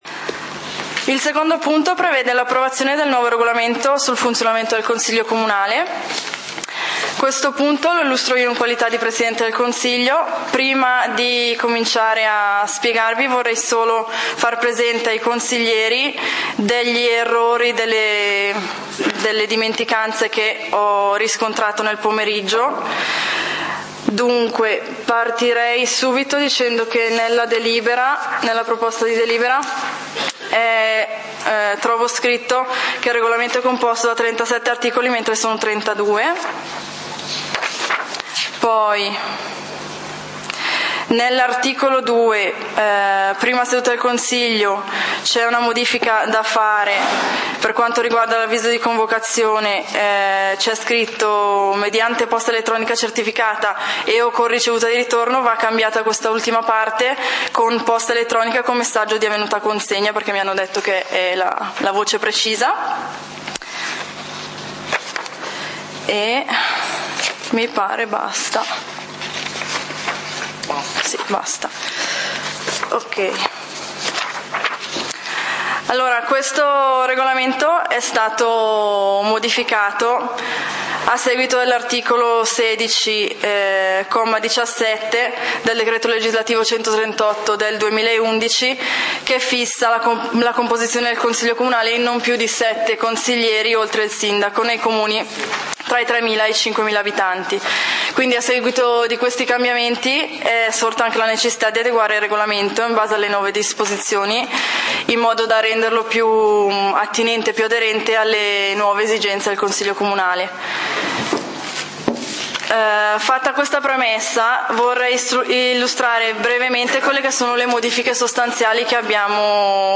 Punti del consiglio comunale di Valdidentro del 27 Settembre 2012